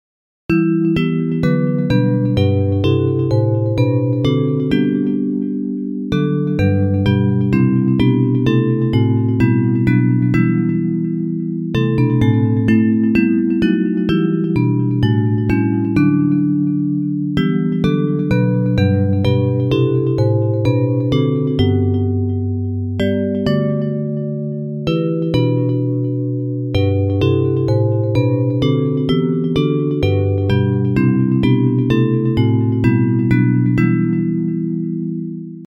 Hymns of praise
Bells Version
Music by: 15th cent. French;